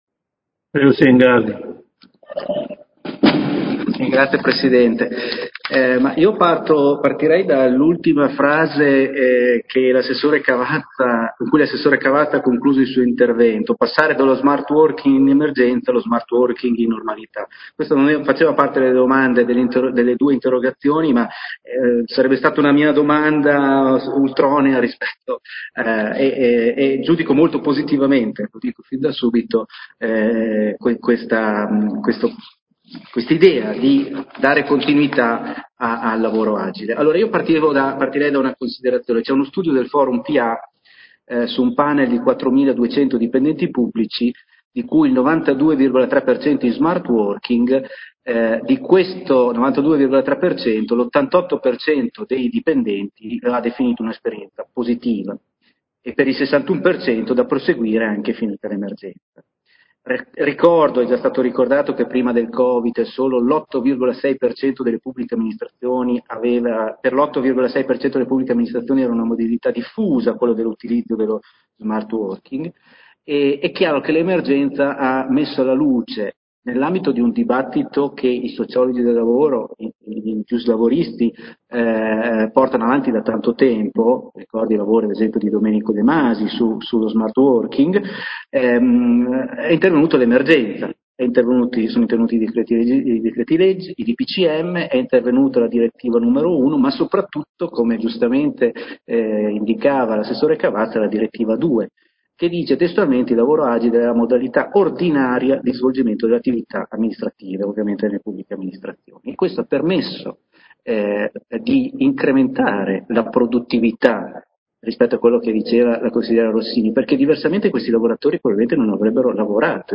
Giovanni Silingardi — Sito Audio Consiglio Comunale